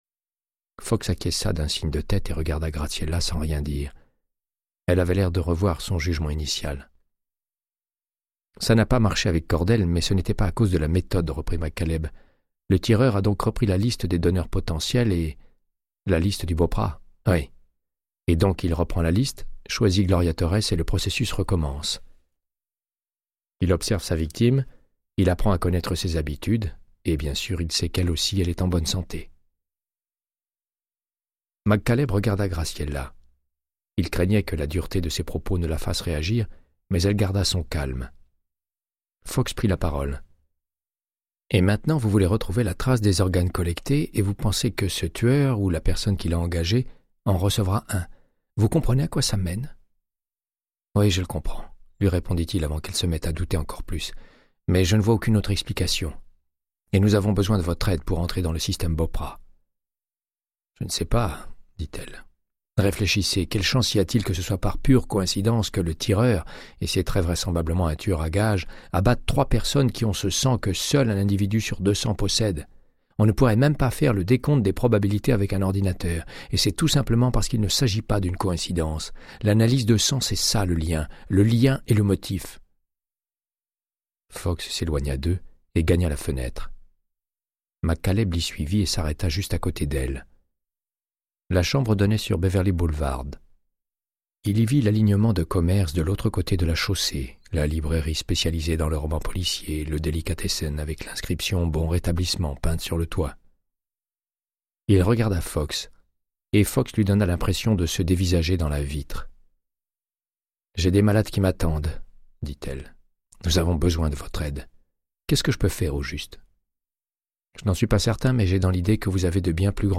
Audiobook = Créance de sang, de Michael Connelly - 119